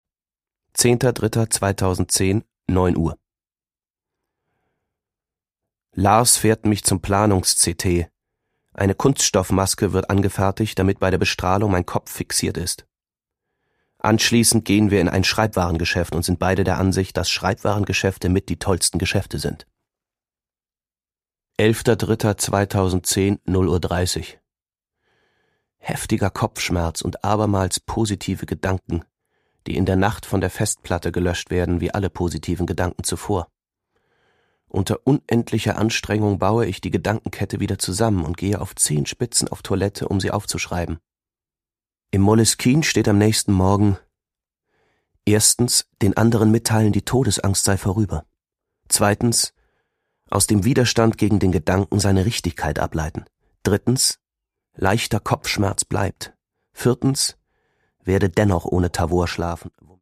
Produkttyp: Hörbuch-Download
Gelesen von: August Diehl